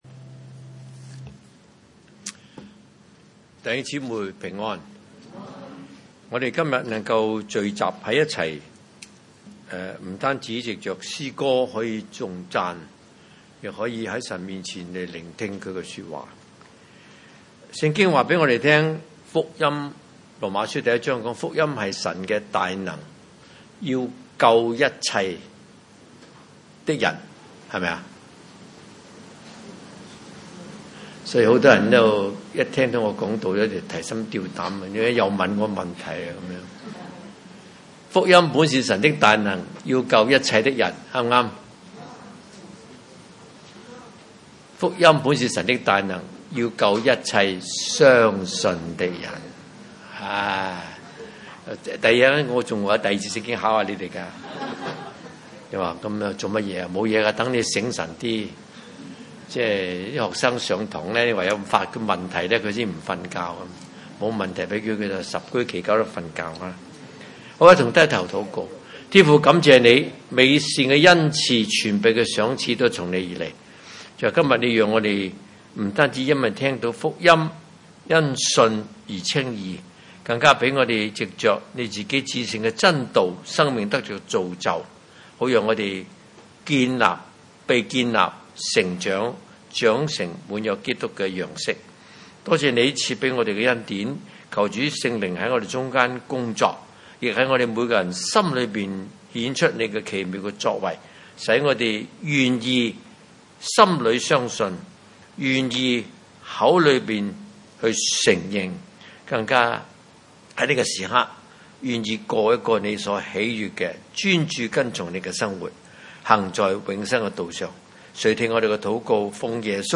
約翰一書1:1-3 崇拜類別: 主日午堂崇拜 彼得前書1:23-25 你們蒙了重生，不是由於能壞的種子，乃是由於不能壞的種子，是藉著神活潑常存的道。